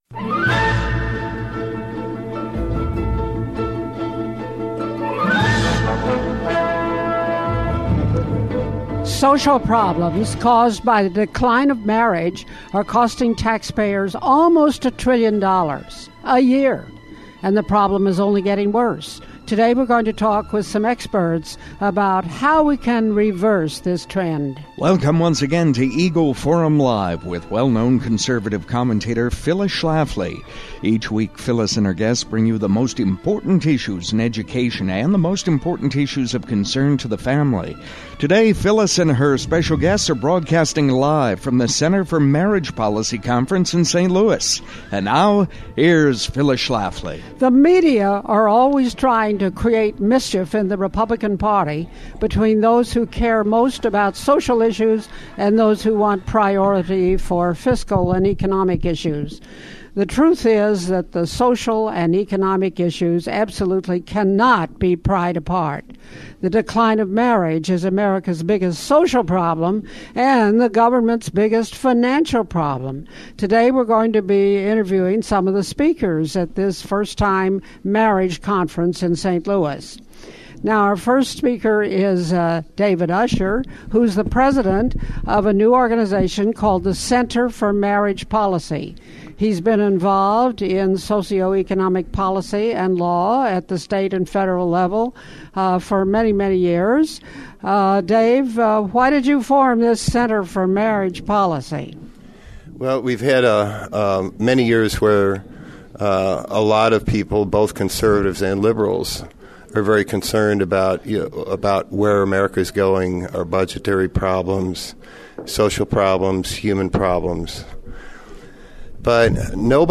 Cynthia is a guest speaker at the launching of the Center for Marriage Policy